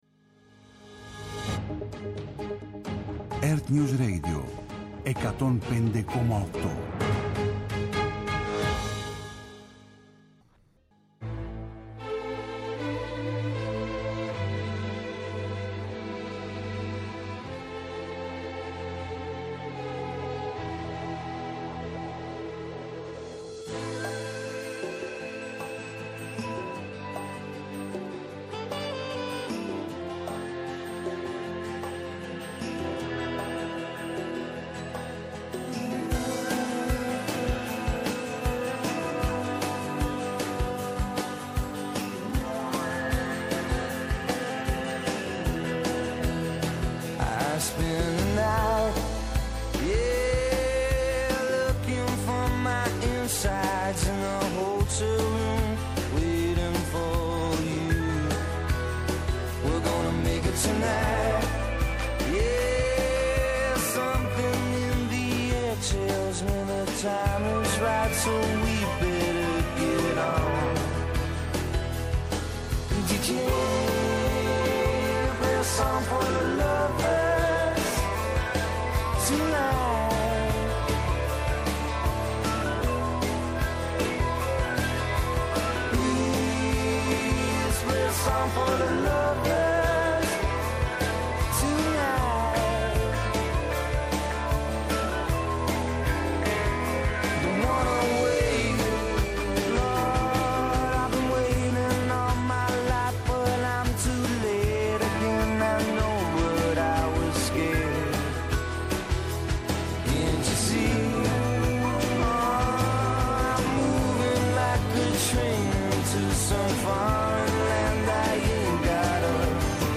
-Ο Δημήτρης Δαρούσης αντιδήμαρχος τεχνικών υπηρεσιών και πολιτικής προστασίας του δήμου Σουφλίου για την υποχώρηση αναχωμάτων και τον κίνδυνο που διατρέχουν να πλημμυρίσουν χωριά στην ευρύτερη περιοχή του Σουφλίου στον Έβρο
ΕΡΤNEWS RADIO